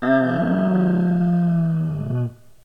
moan-2.ogg